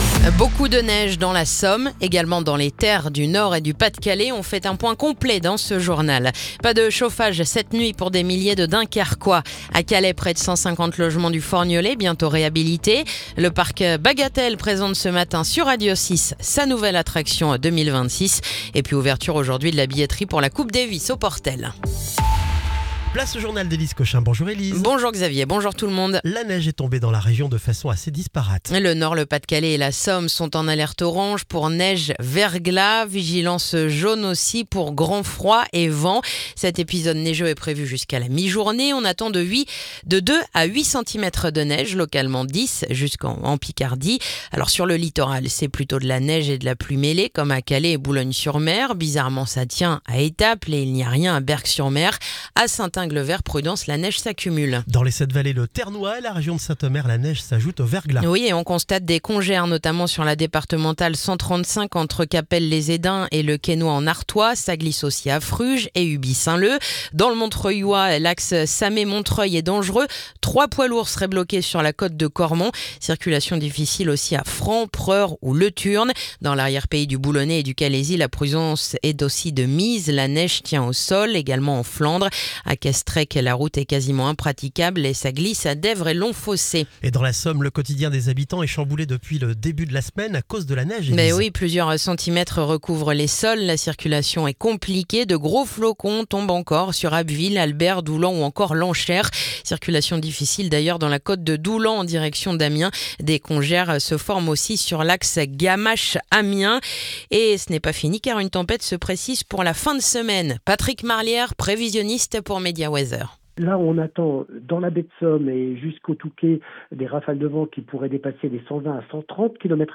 Le journal du mercredi 7 janvier